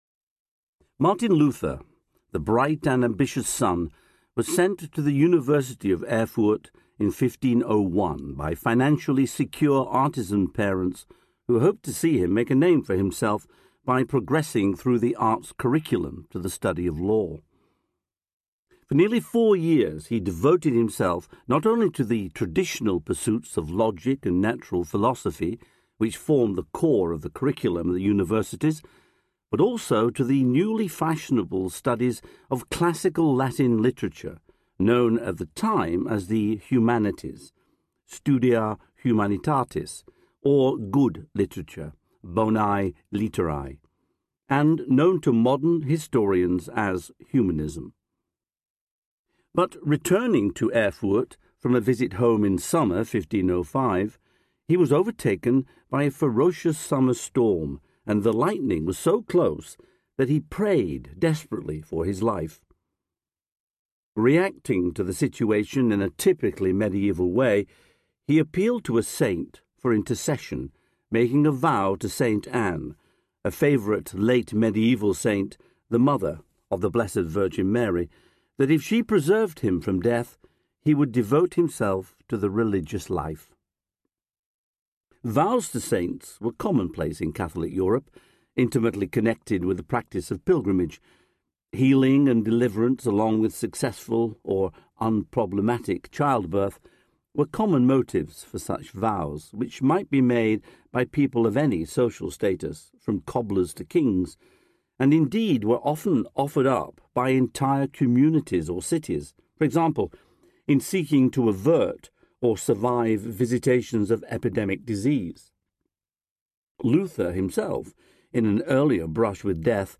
The Making of Martin Luther Audiobook
Narrator